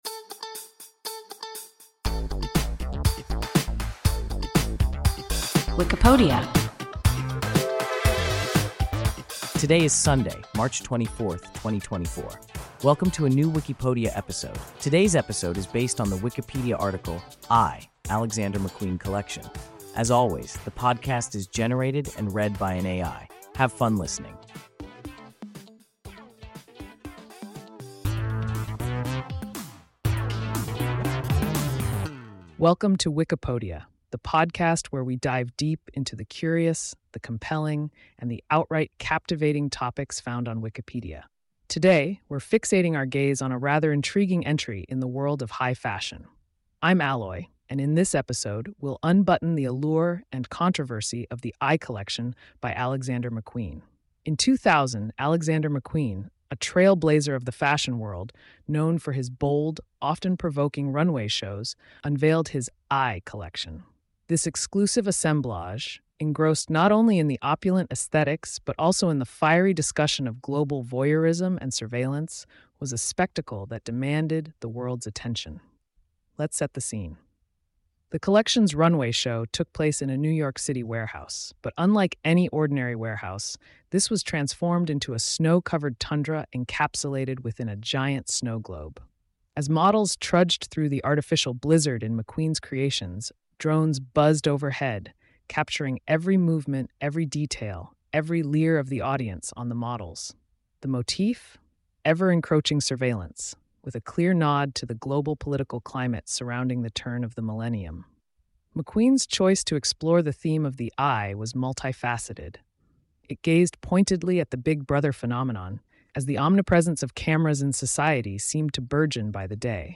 Eye (Alexander McQueen collection) – WIKIPODIA – ein KI Podcast